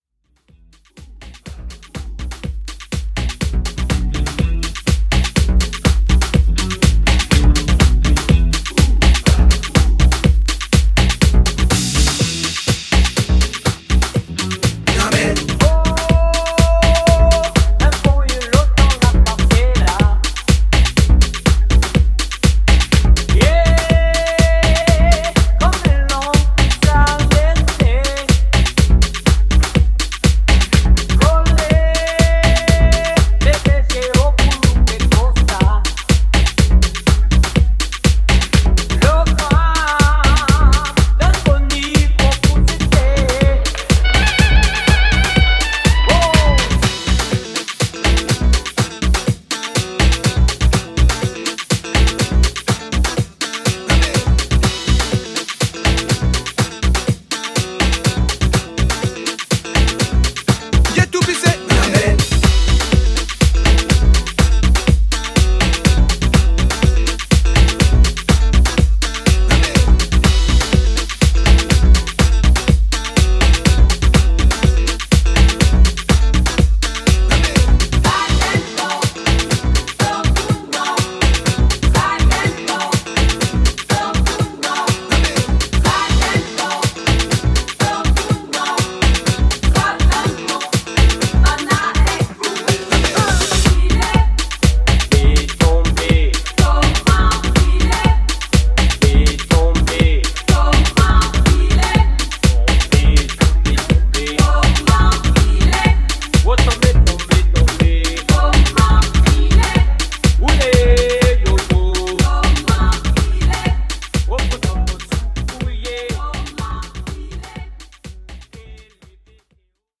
Afro house and disco